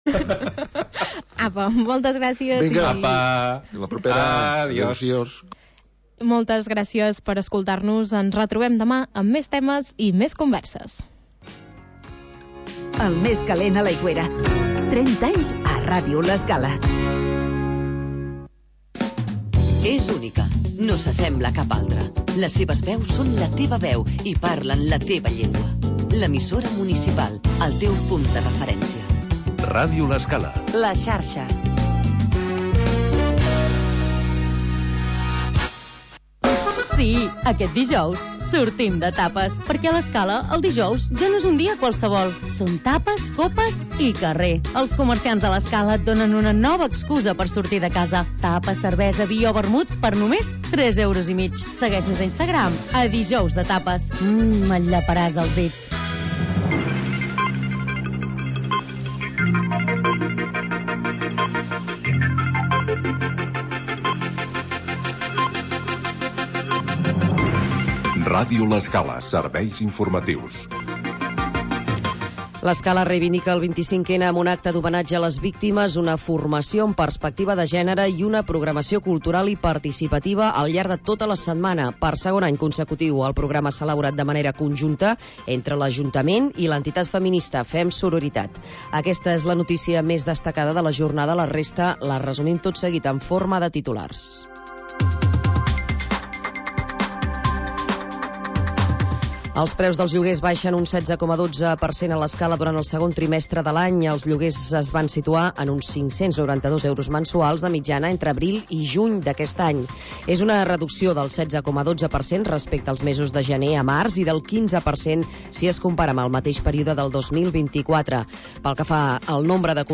Noticiari d'informació local